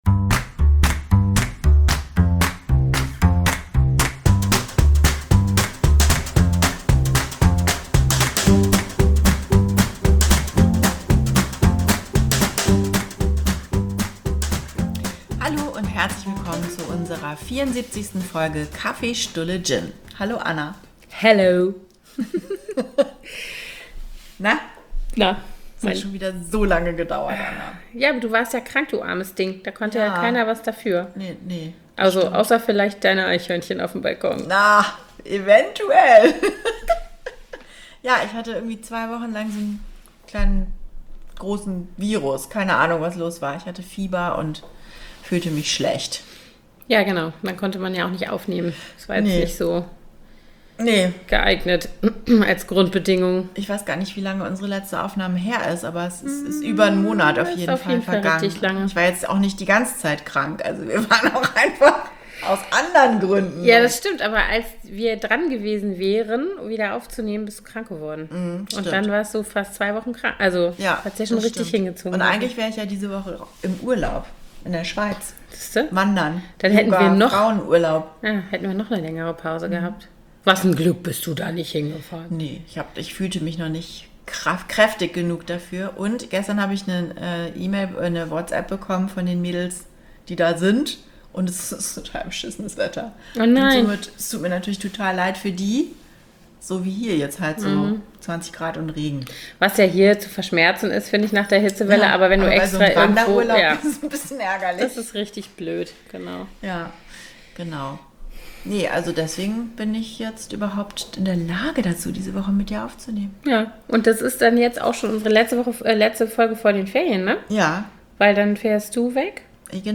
zwei Freundinnen im echten Leben, beide jenseits der 40 - quatschen gerne und viel und nehmen sich wöchentlich neue Themen vor